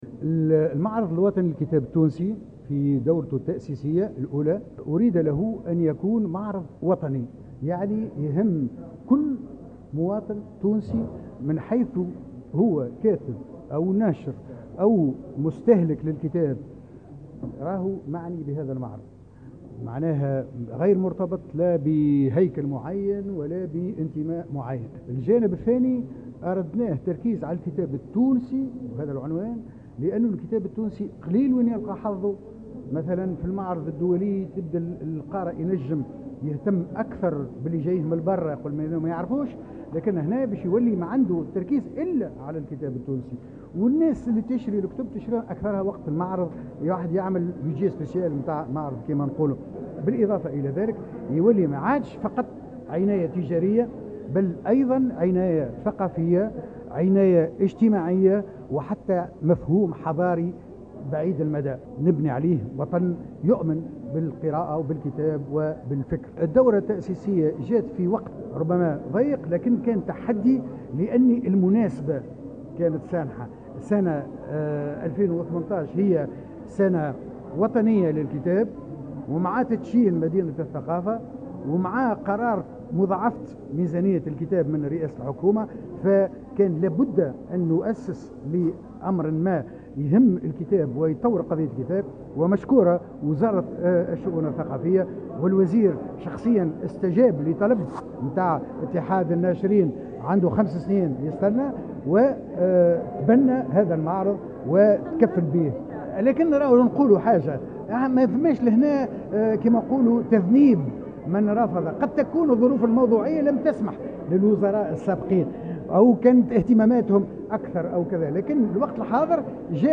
الندوة الصحفية التي انتظمت صباح الثلاثاء 16 أكتوبر 2018 بقاعة صوفي القلي بمدينة الثقافة